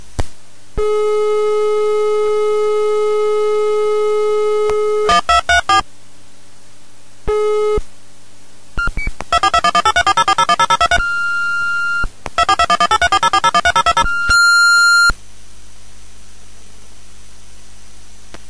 AdemcoContactID.mp3